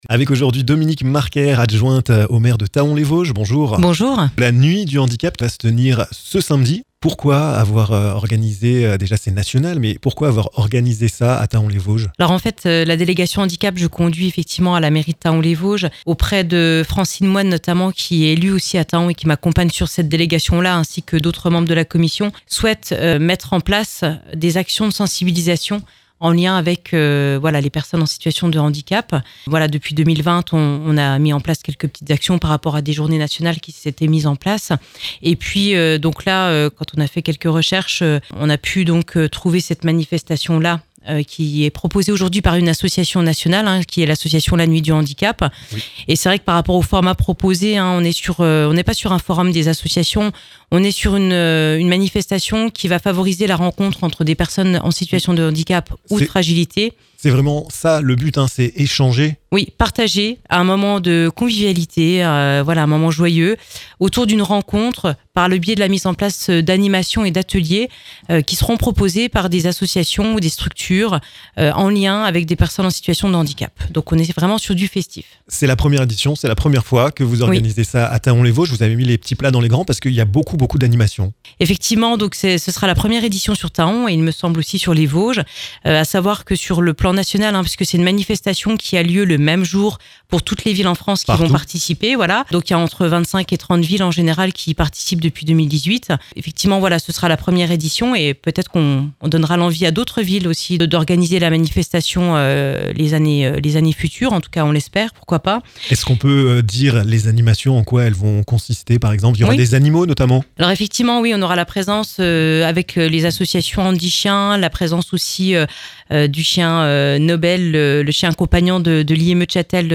Dominique Marquaire, adjointe au maire de Thaon-les-Vosges en charge de l'insertion et du handicap, vous explique le déroulement de cette soirée!